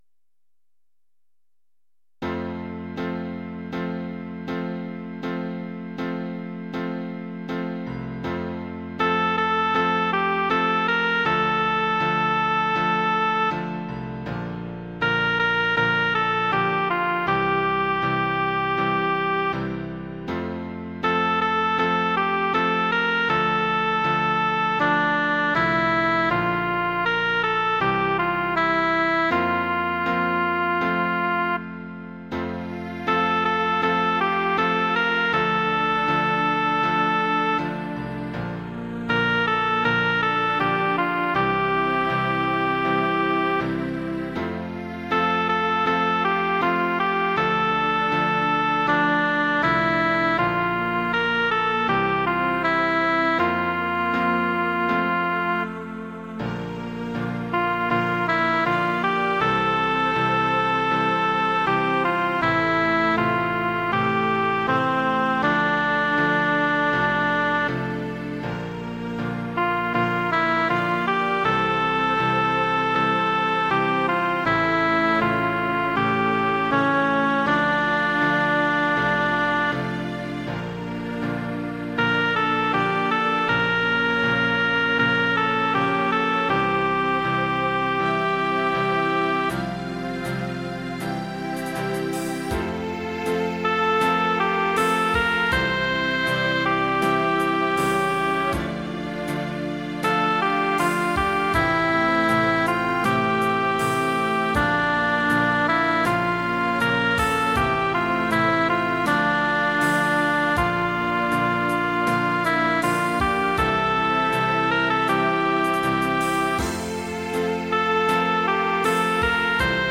オリジナルピアノバラード
ピアノ、ストリングス
バラードっぽいですが…ちょっと微妙？